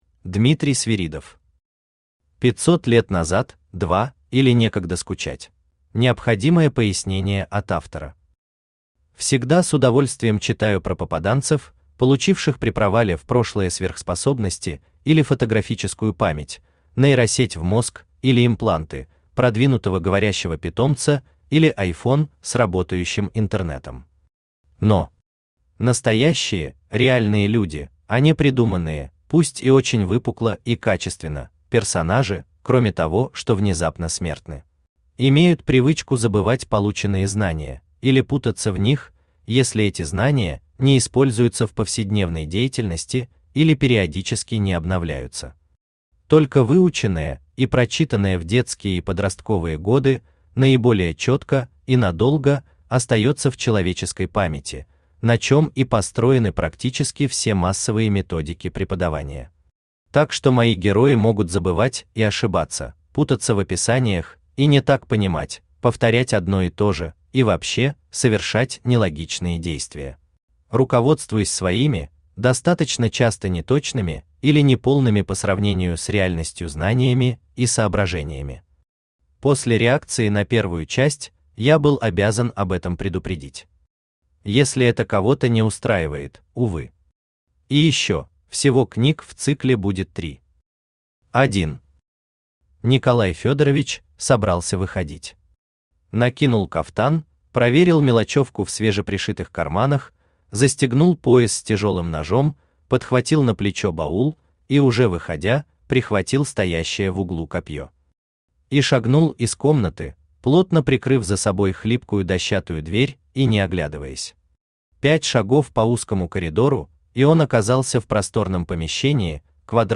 Аудиокнига 500 лет назад – 2, или Некогда скучать | Библиотека аудиокниг
Aудиокнига 500 лет назад – 2, или Некогда скучать Автор Дмитрий Свиридов Читает аудиокнигу Авточтец ЛитРес.